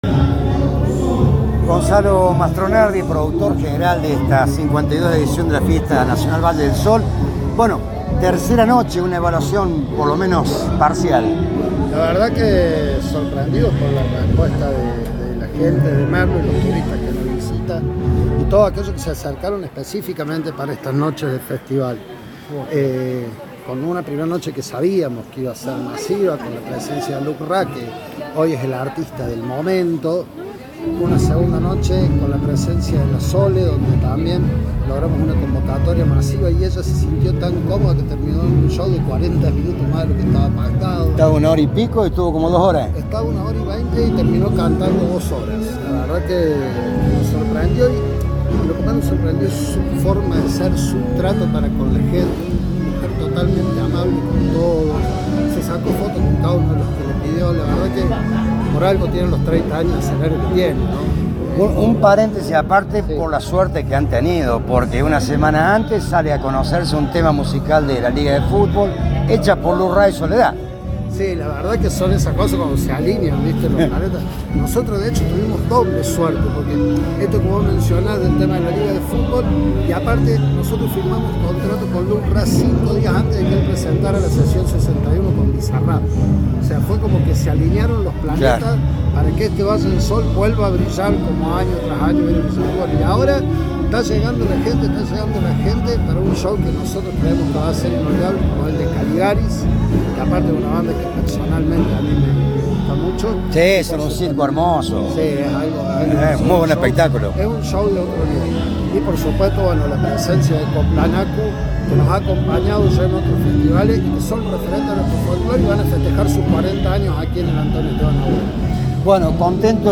en medio de la tercera noche de lo que a esa altura ya era un éxito absoluto de la 52 edición de esta fiesta musical y cultural que no para de crecer.